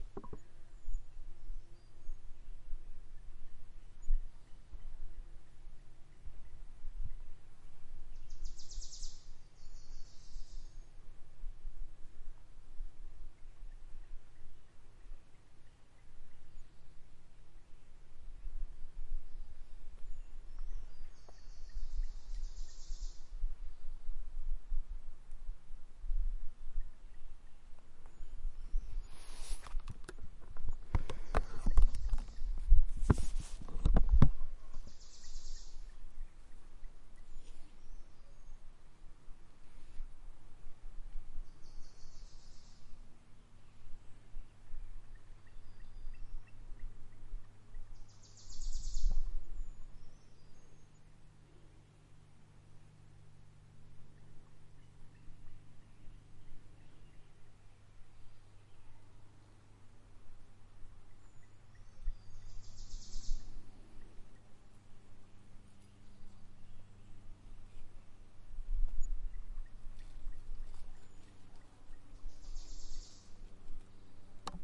forest » sfx amb forest spring afternoon01
描述：Heavy birdy forest ambiance. Spring, afternoon.Location: Bratislava Koliba, SlovakiaRecorded by Olympus LS11
标签： birdsong birds ambience ambient forest bird ambiance spring nature fieldrecording generalnoise atmosphere
声道立体声